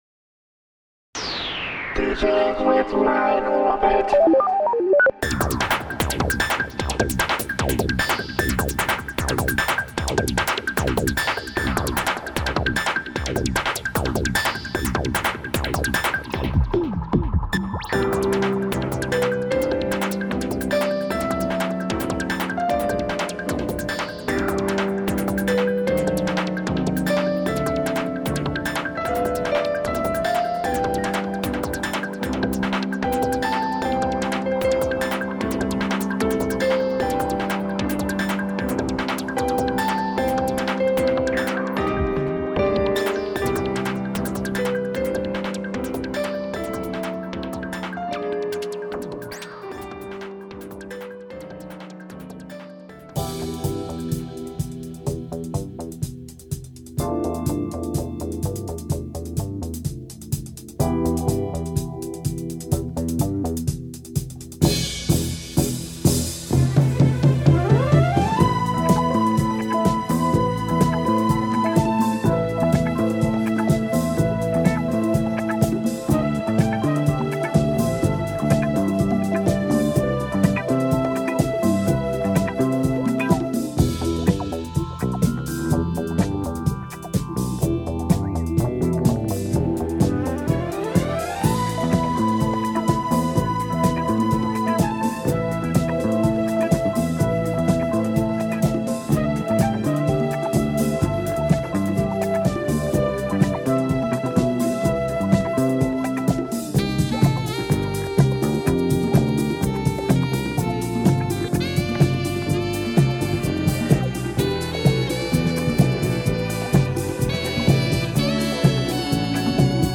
Wah Wah Effect is good for cold winter (part 3).